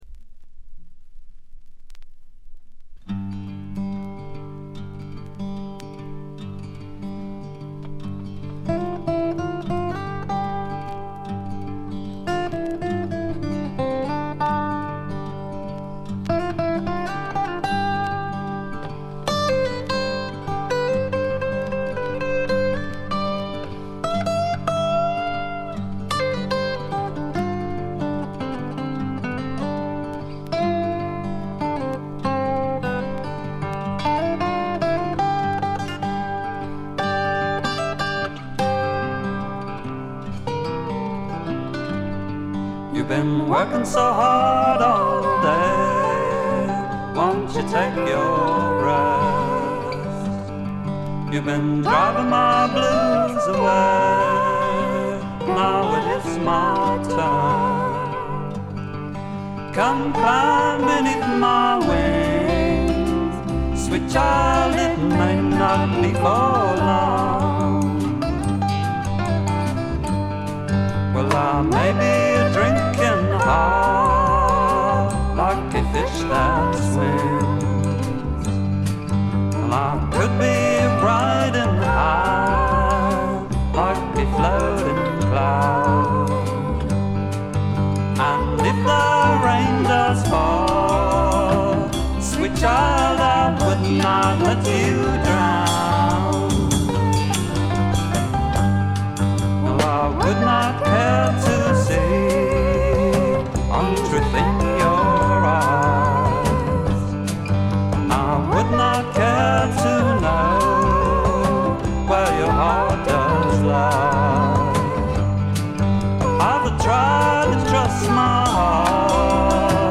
試聴曲は現品からの取り込み音源です。
Sides 3 and 4, Recorded at IBC Studios,London, August 1968.